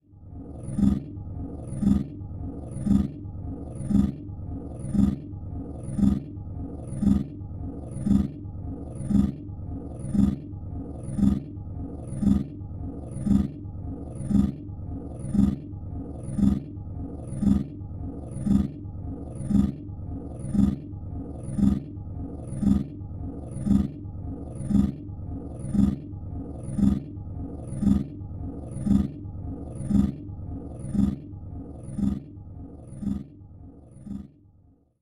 Звуки фантастики
Звуковое сканирование и одновременная лазерная починка тела человека или робота